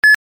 BEEP
beep.mp3